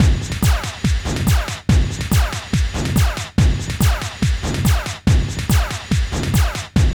06.1 LOOP2.wav